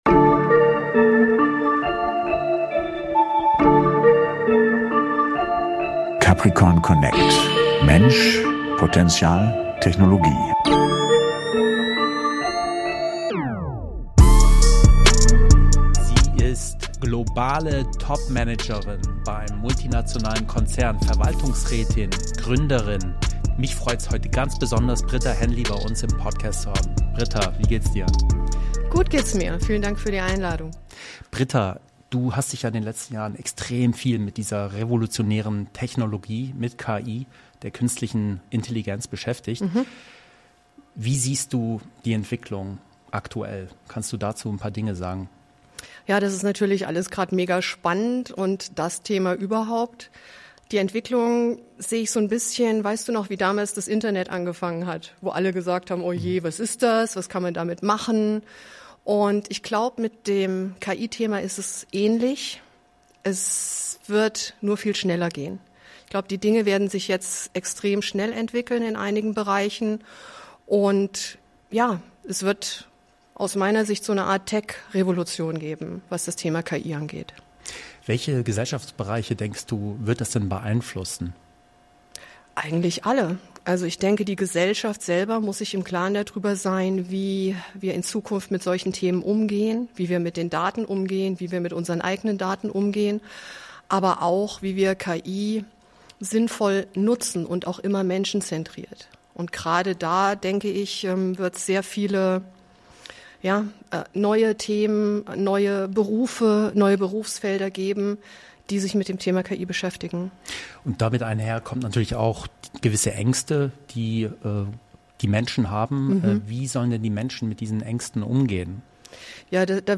Ein inspirierendes Gespräch über Technologie, Leadership und die Zukunft der Arbeit.